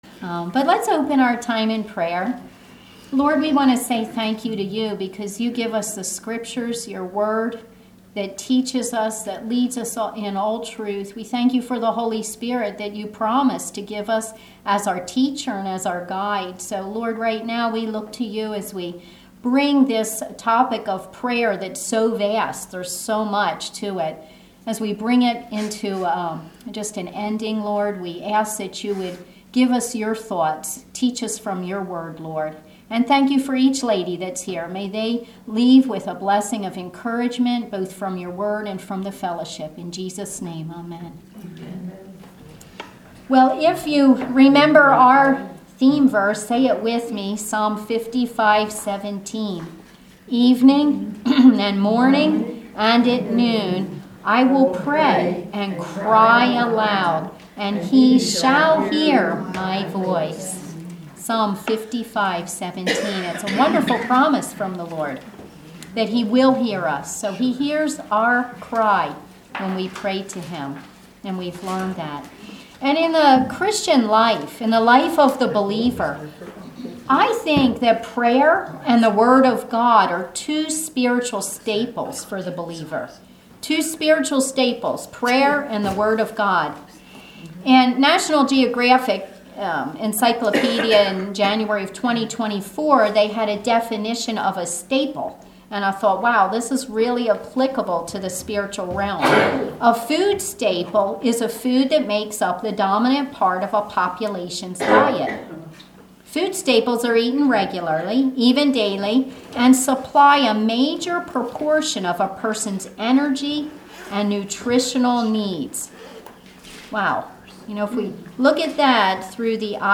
Service Type: Ladies Bible Study